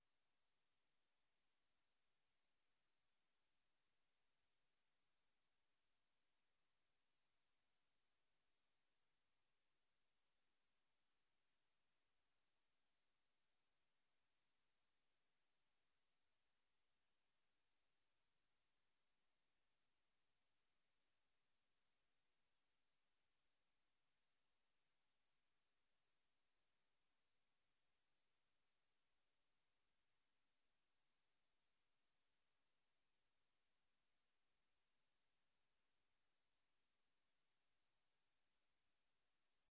Raadzaal